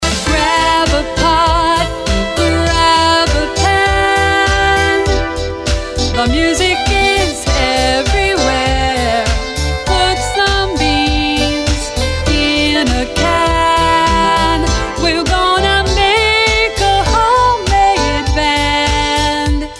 to several instruments of a jazz or rock band.